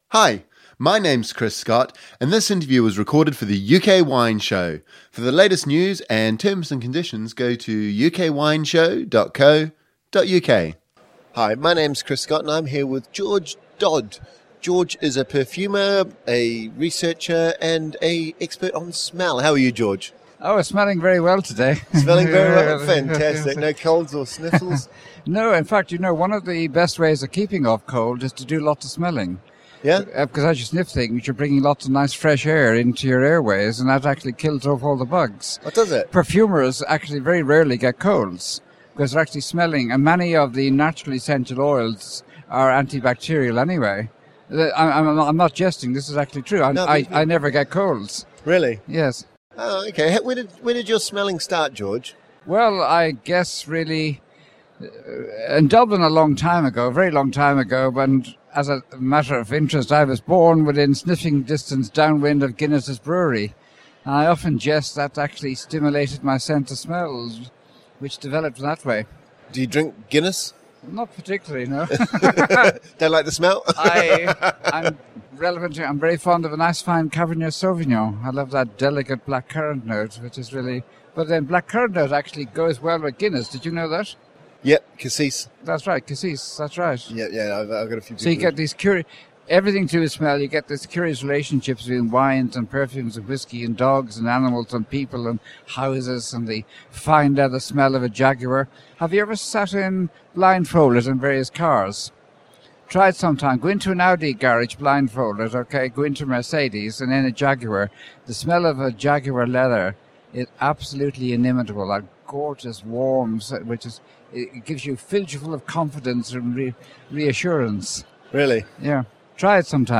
In this first interview of four we hear our sense of smell works.